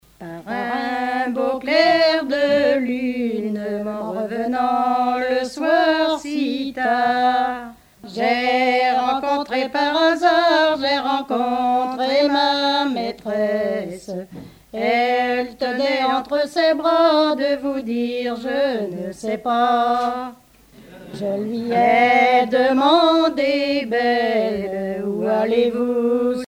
Genre strophique
Chansons traditionnelles